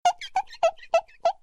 Download Free Youtube Funny Sound Effects